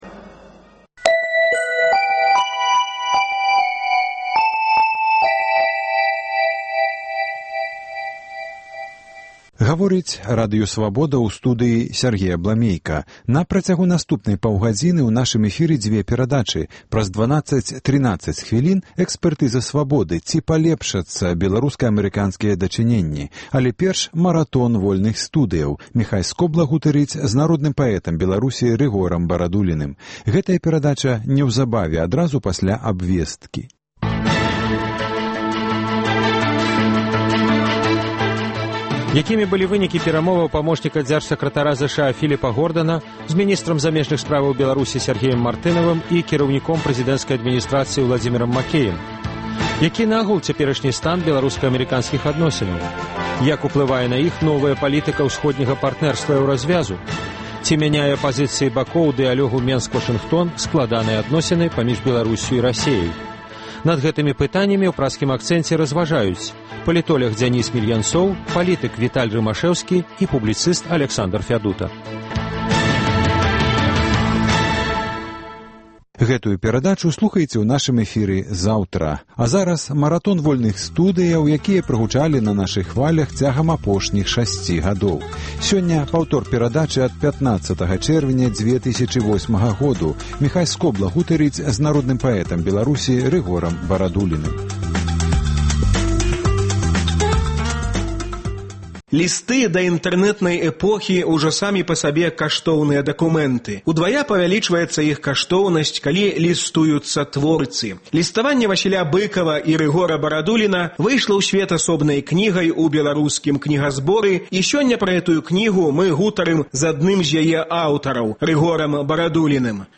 Сёньня гутарка з народным паэтам Беларусі Рыгорам Барадуліным. "Экспэртыза Свабоды": ці палепшацца беларуска-амэрыканскія дачыненьні пасьля візыту ў Беларусь памочніка дзяржсакратара ЗША Філіпа Гордана?